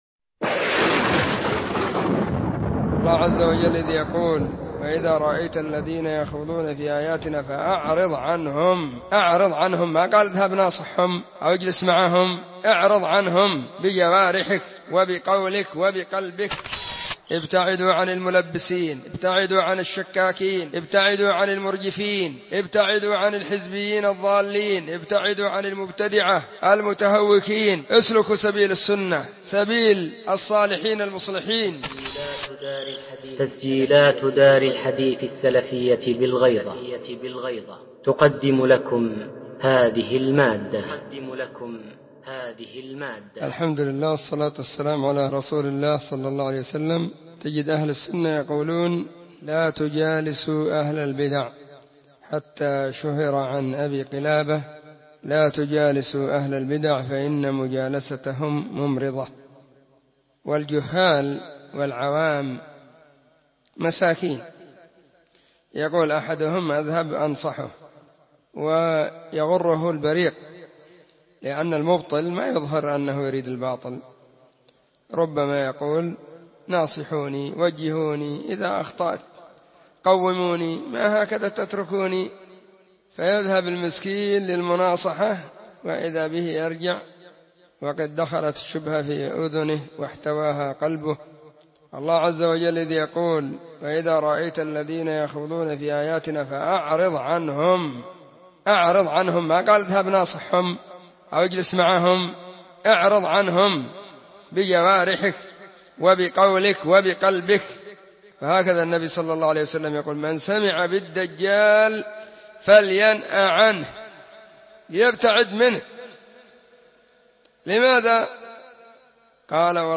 🎙كلمة بعنوان:كونوا على حذر من تلبيس إبليس*
📢 مسجد الصحابة – بالغيضة – المهرة، اليمن حرسها الله.